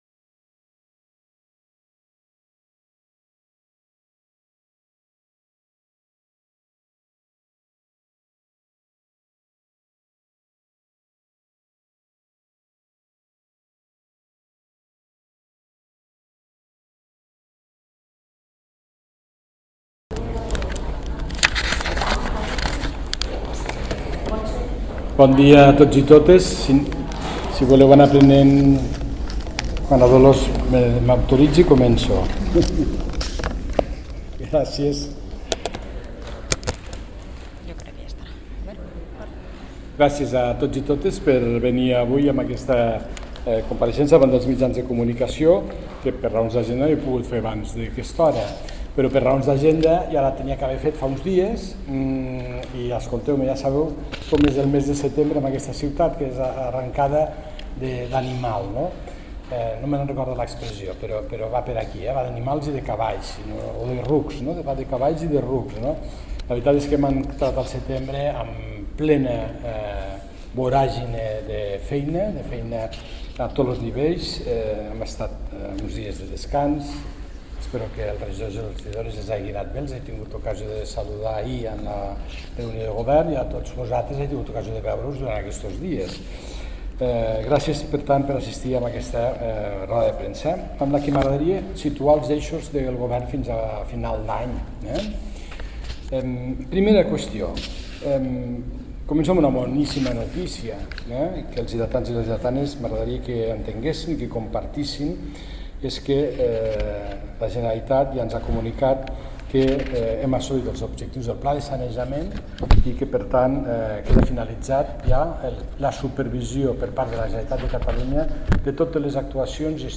El paer jefe, Fèlix Larrosa, acompañado de los miembros de su equipo de gobierno, compareció este viernes en rueda de prensa para exponer los principales ejes de actuación del gobierno municipal para los próximos meses, con especial énfasis en la gestión económica, la vivienda, la seguridad, la convivencia y el desarrollo urbanístico y empresarial de la ciudad.